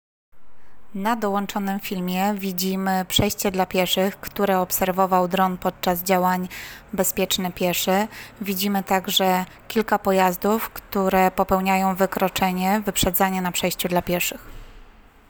Nagranie audio audiodeskrypcja fimu